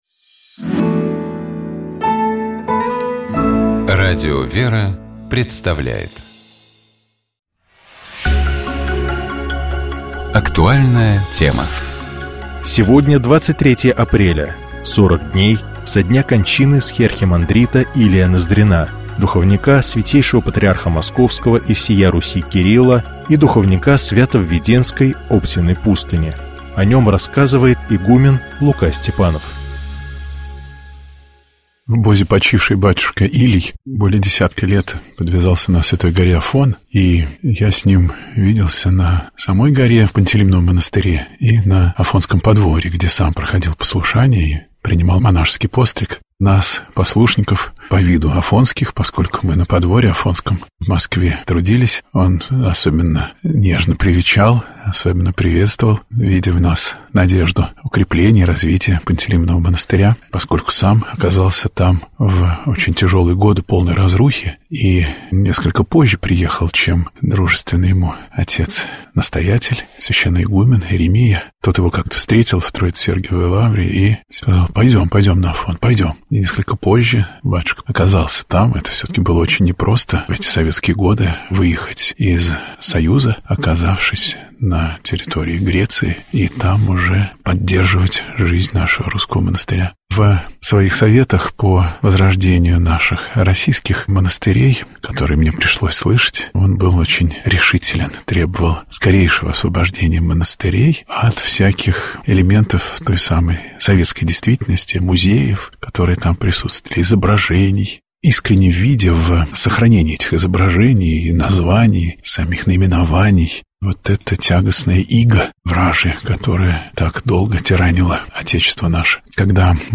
На Сретение Господне в храмах поётся кондак, — то есть песнопение, описывающее события этого праздника. Давайте поразмышляем над текстом кондака Сретения, и послушаем его отдельными фрагментами в исполнении сестёр Орского Иверского женского монастыря.